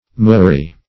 Search Result for " moory" : The Collaborative International Dictionary of English v.0.48: Moory \Moor"y\, n. A kind of blue cloth made in India.